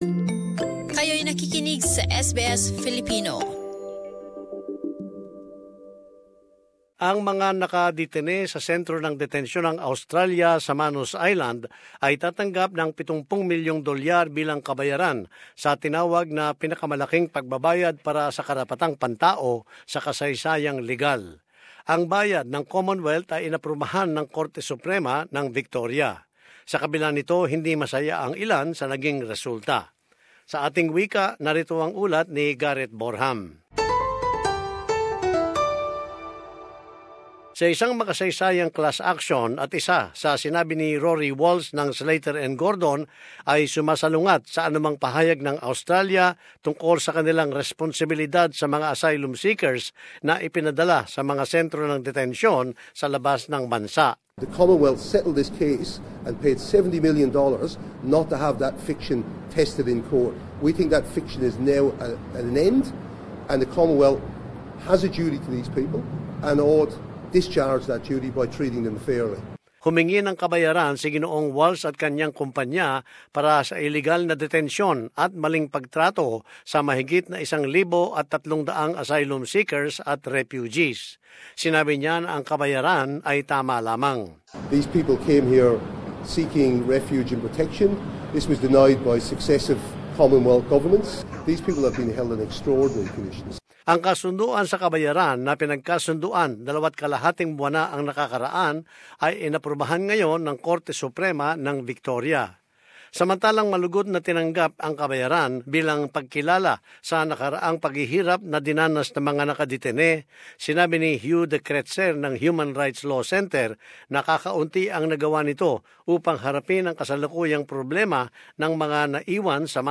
But as this report shows, not everyone is happy with the outcome.